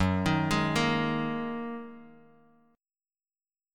F#6add9 Chord
Listen to F#6add9 strummed